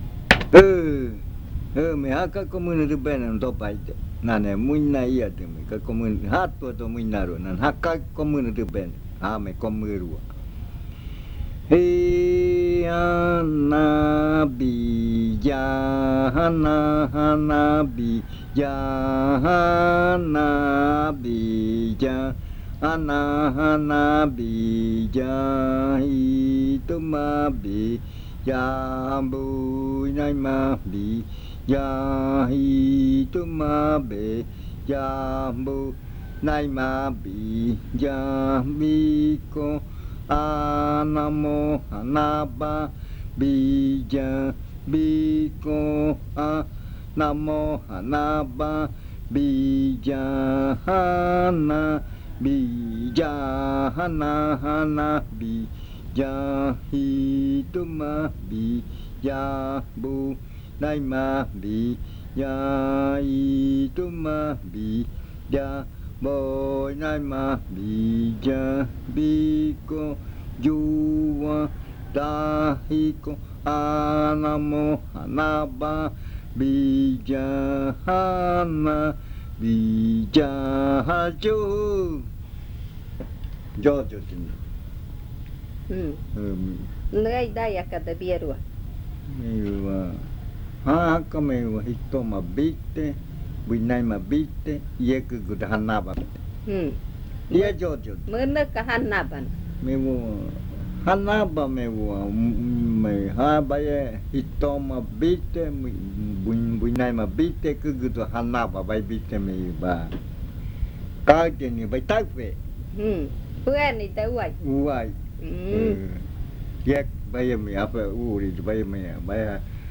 Leticia, Amazonas
Este canto hace parte de la colección de cantos del ritual Yuakɨ Murui-Muina (ritual de frutas) del pueblo Murui
This chant is part of the collection of chants from the Yuakɨ Murui-Muina (fruit ritual) of the Murui people